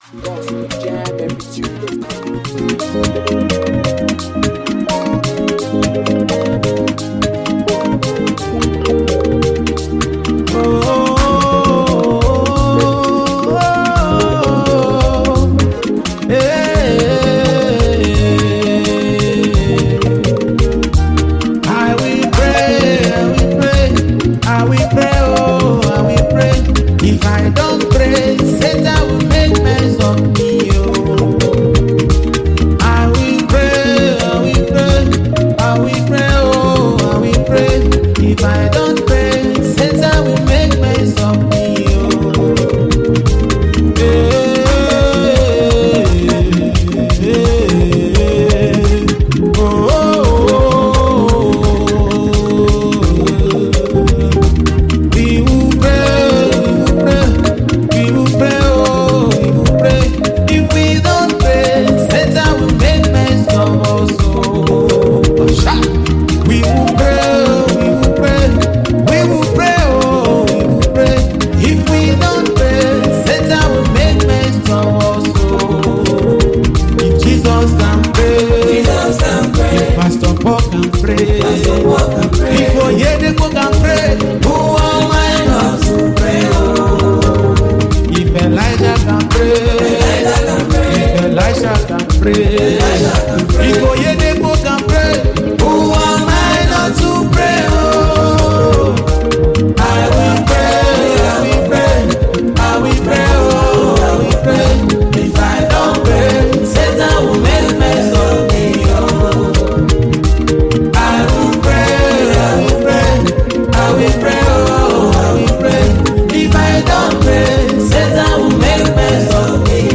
heartfelt gospel sound
With deep lyrics and a spirit-filled melody